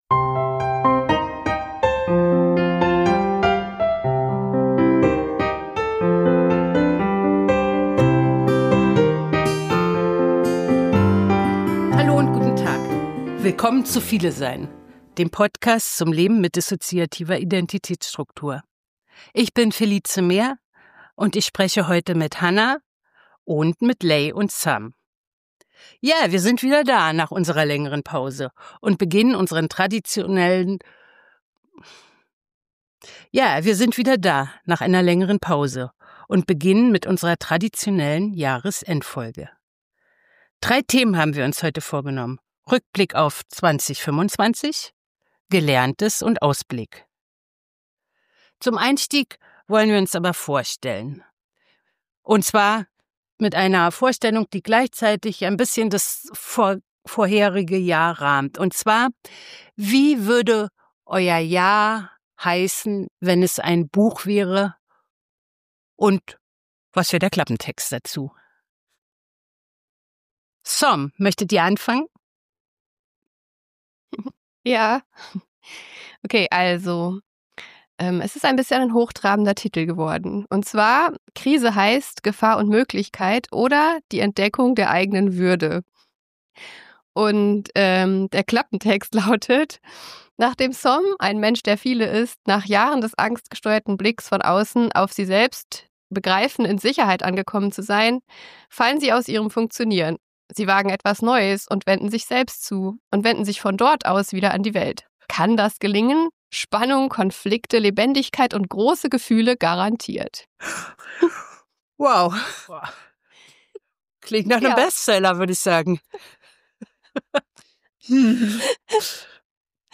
Diesen Jahreswechsel begehen wir in großer Runde